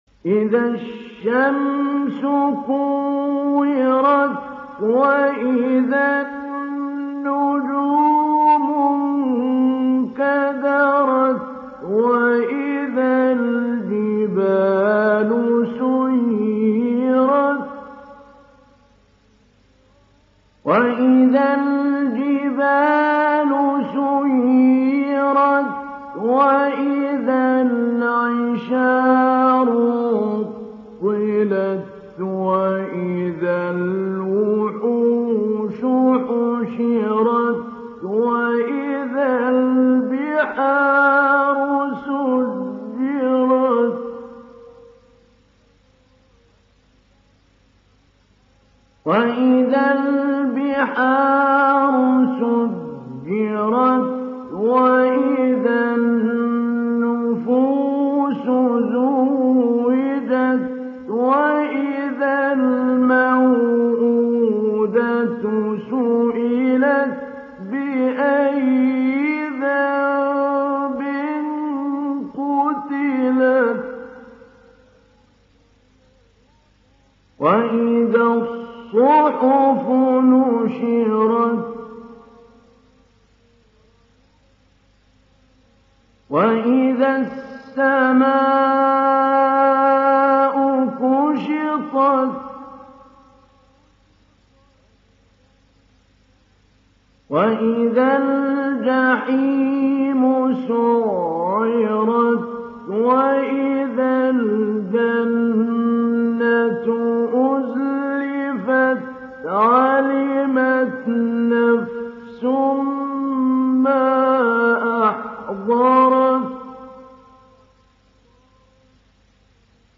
Download Surah At Takwir Mahmoud Ali Albanna Mujawwad